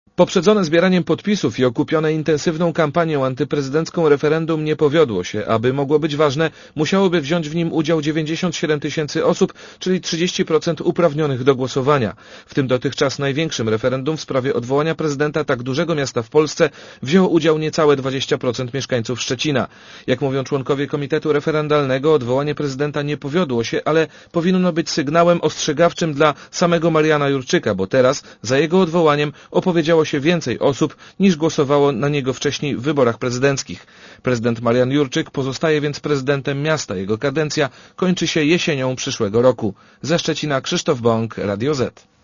Relacja reportera Radia ZET Referendum byłoby ważne wtedy, gdyby wzięło w nim udział co najmniej 30% z blisko 323 tys. uprawnionych do głosowania, czyli ok. 96 tysięcy szczecinian.